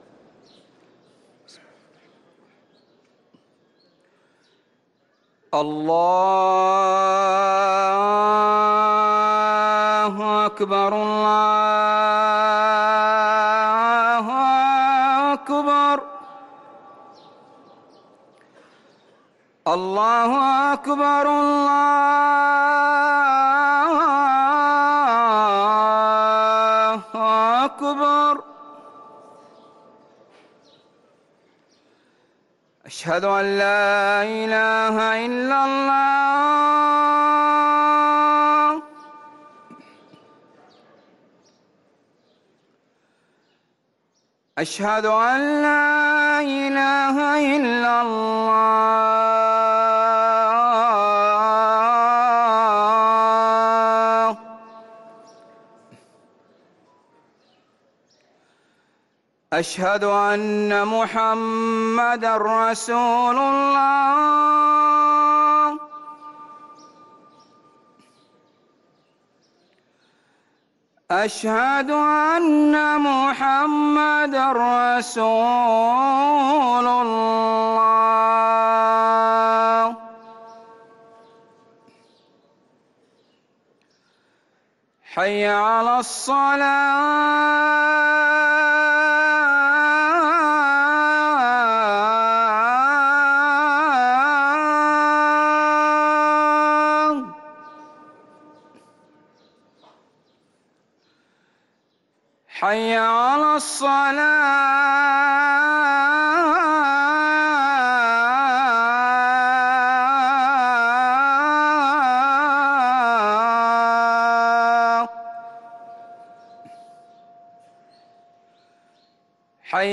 أذان المغرب للمؤذن